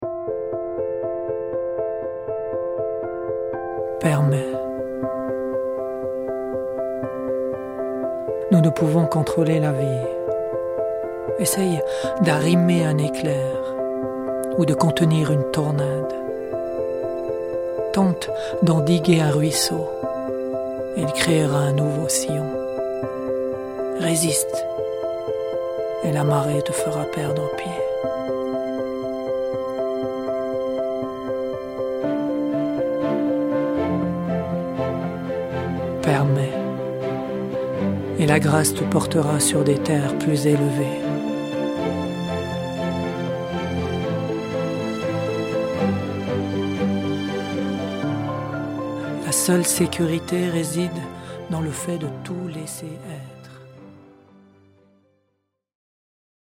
Au travers de ces poèmes accompagnés de musiques inspirantes, nous souhaitons vous offrir des moments d’élévation, de ressourcement et de joie profonde.